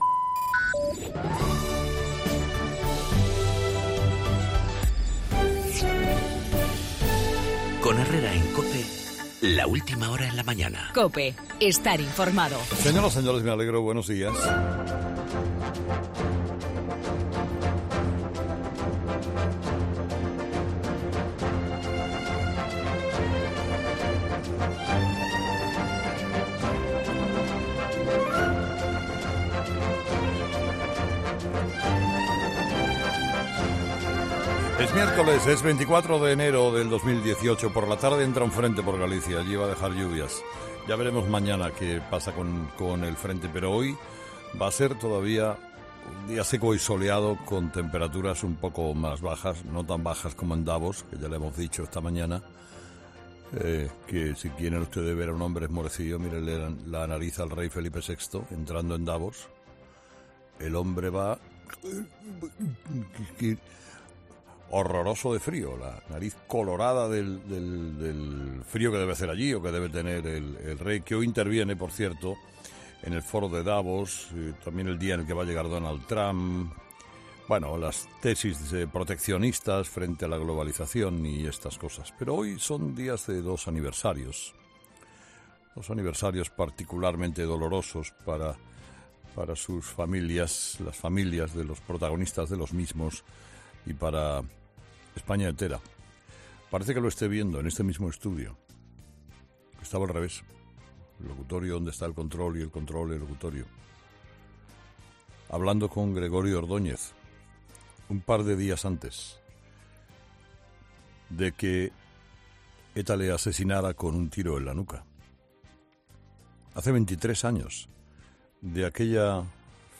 Monólogo de las 8 de Herrera
Escucha el comentario de Herrera del 24 de enero de 2018